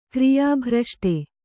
♪ kriyā bhraṣṭe